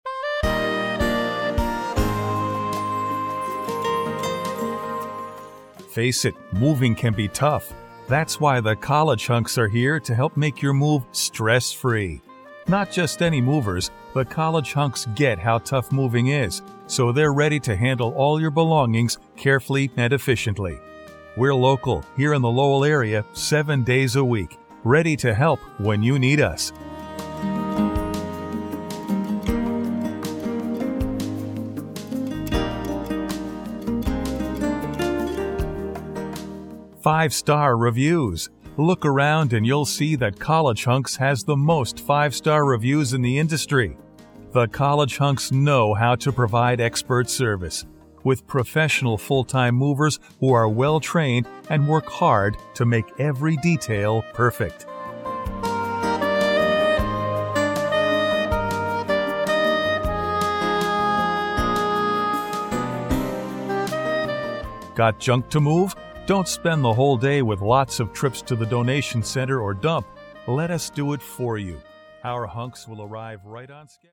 Here are samples of what a professional voice can sound like at your business
Informer Professional Greetings
When the script is final and approved, a professional voiceover will record your telephone greeting.